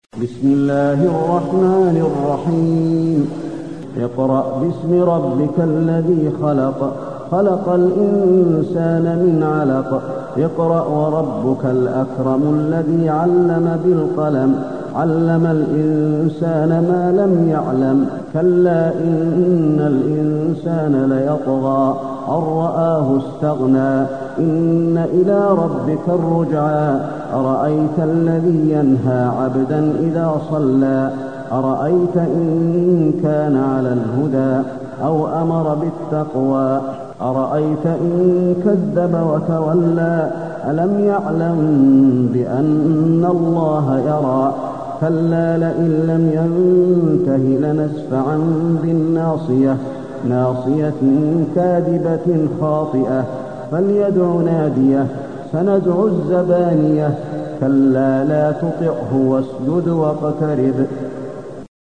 المكان: المسجد النبوي العلق